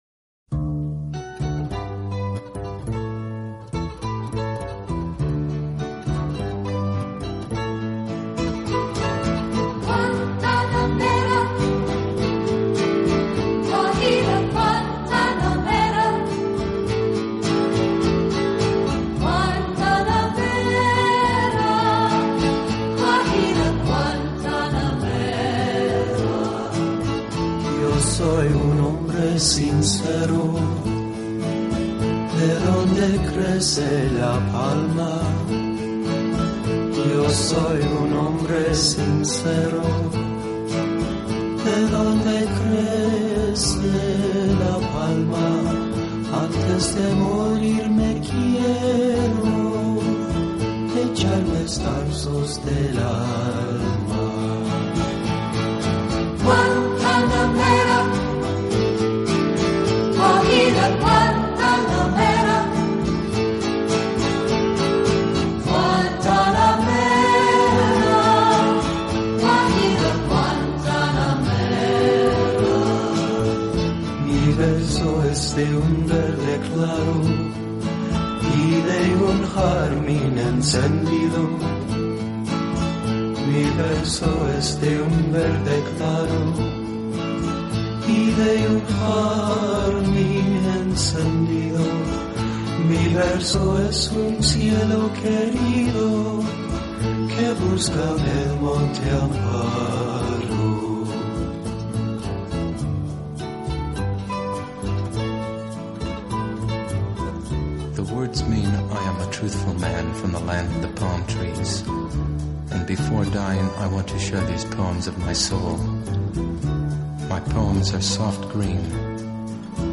情感，时而恬静美妙，时而跳跃激昂；气势，如行云流水，如浪潮澎湃；史上最
浪漫、最优美的纯音乐，超时空经典，超想像完美。